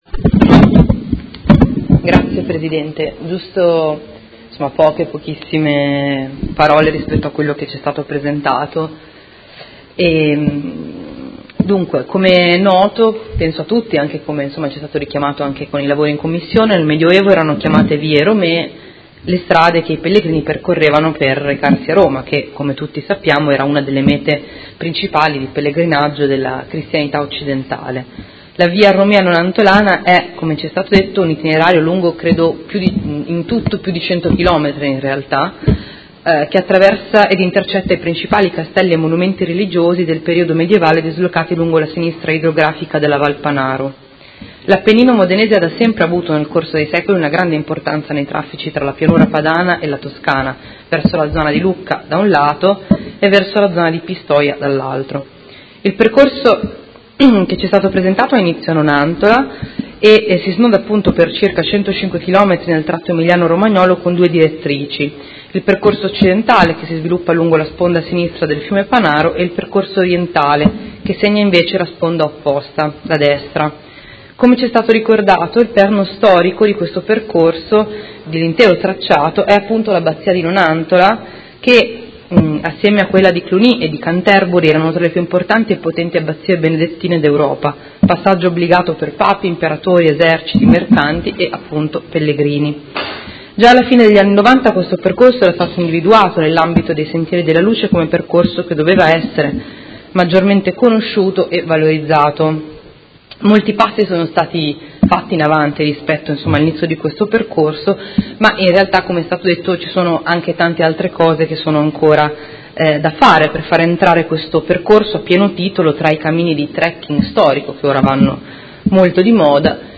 Seduta del 12/04/2018 Dibattito. Adesione al Progetto di manutenzione e promozione coordinata della Via Romea Nonantolana e approvazione della relativa Convenzione